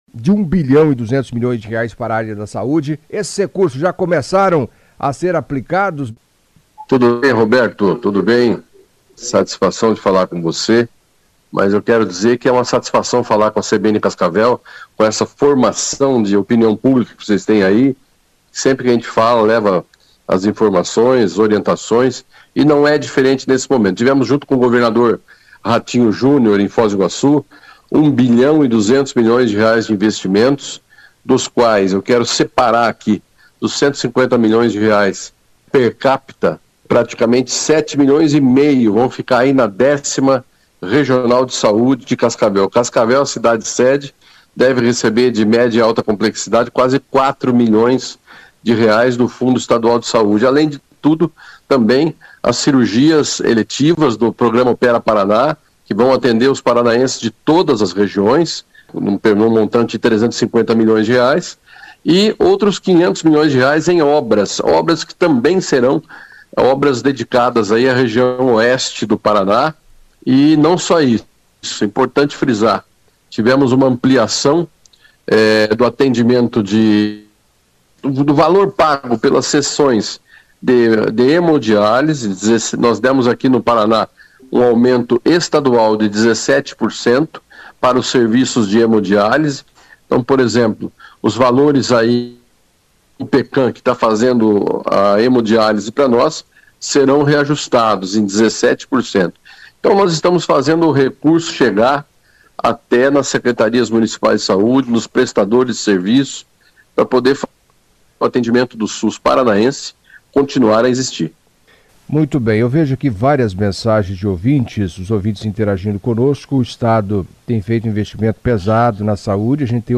Em entrevista à CBN Cascavel nesta segunda-feira (24) Beto Preto, secretário de Saúde do Paraná, falou do repasse do montante de R$ 1,2 bilhão anunciado no último dia 13, em Foz do Iguaçu, destacando os recursos para Cascavel.
Player Ouça Beto Preto, secretário de Saúde do Paraná